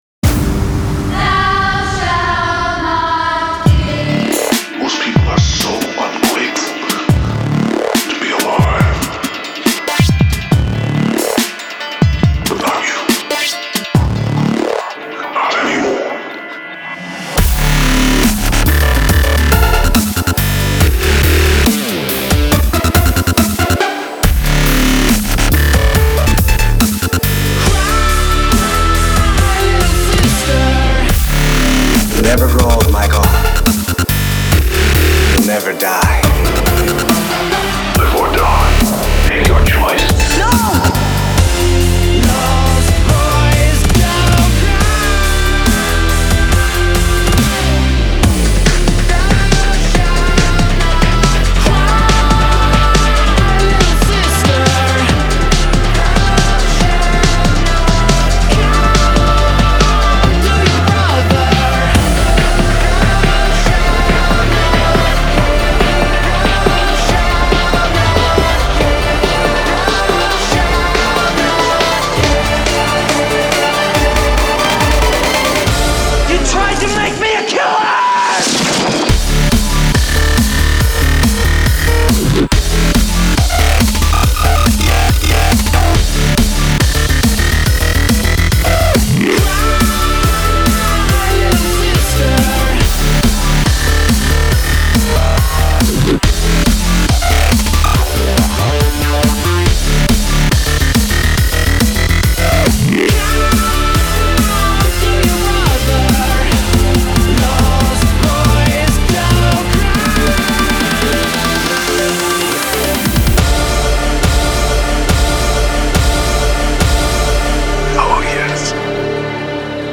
BPM35-140
MP3 QualityMusic Cut